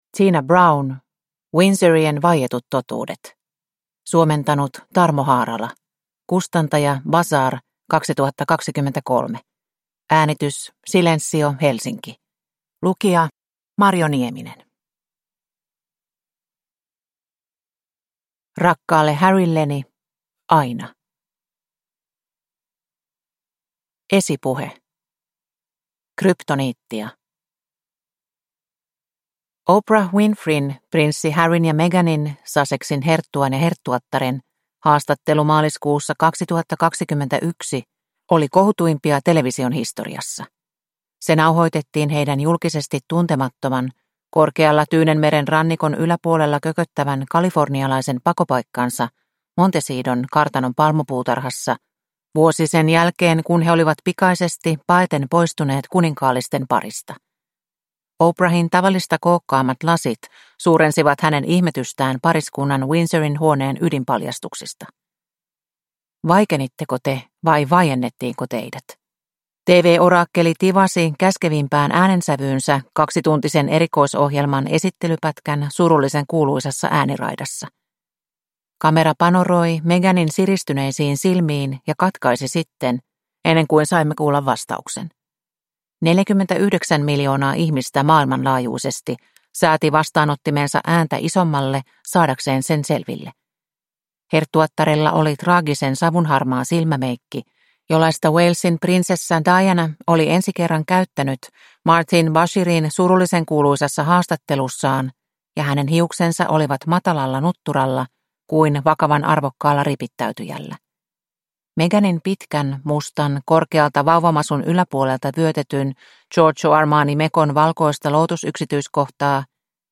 Windsorien vaietut totuudet – Ljudbok